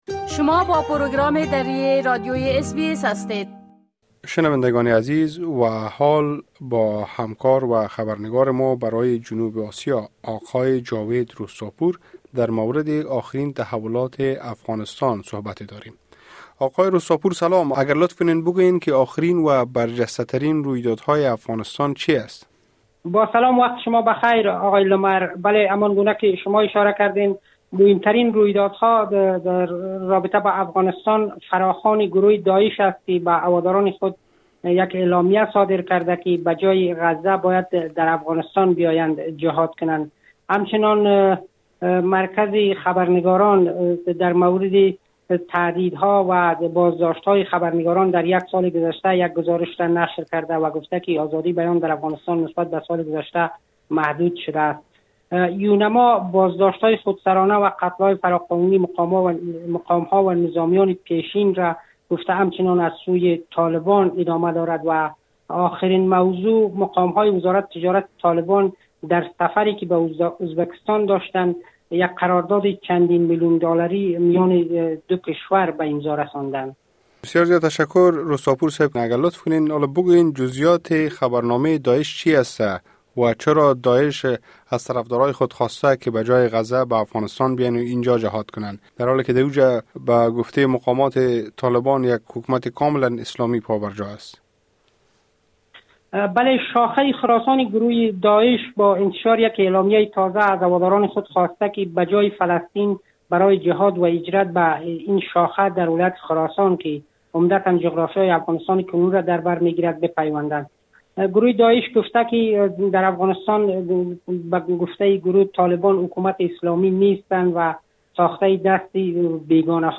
گزارش كامل خبرنگار ما، به شمول اوضاع امنيتى و تحولات مهم ديگر در افغانستان را در اينجا شنيده مى توانيد.